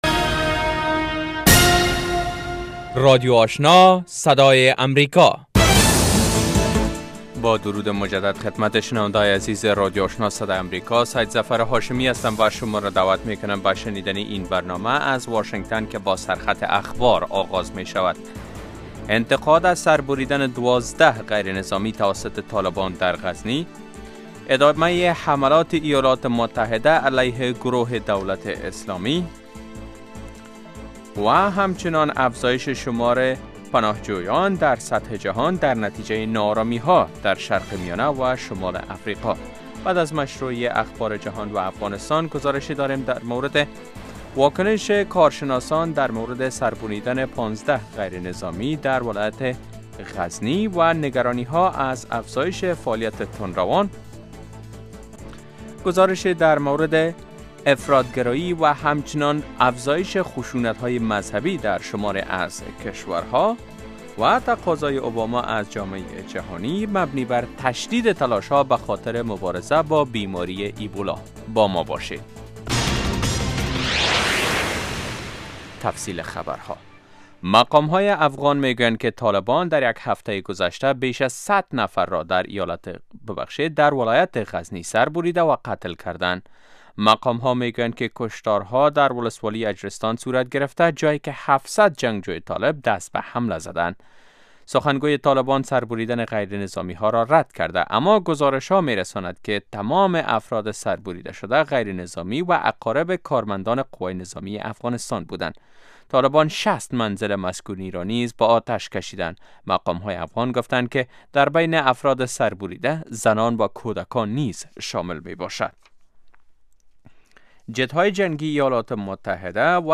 morning news show second part